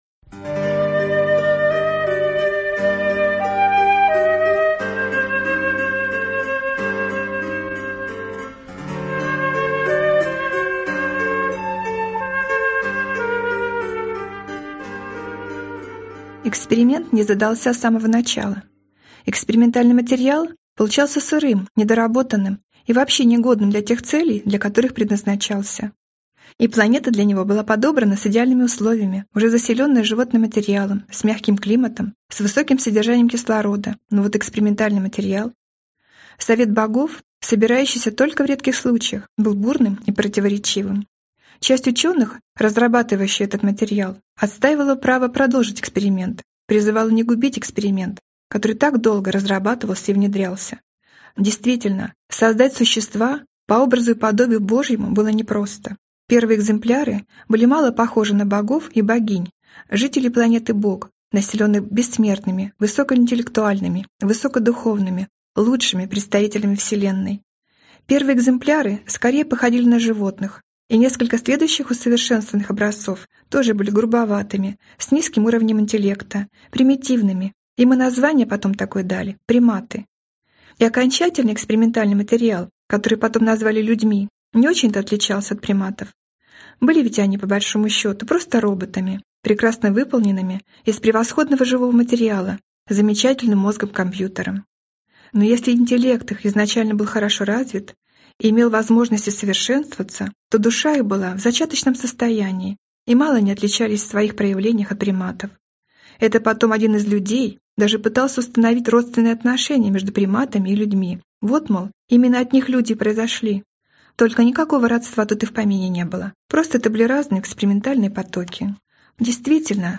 Аудиокнига Конец Света по-Божески | Библиотека аудиокниг